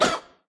Media:RA_Dragon_Chicken_atk_clean_005.wav 攻击音效 atk 局内攻击音效
RA_Dragon_Chicken_atk_clean_004.wav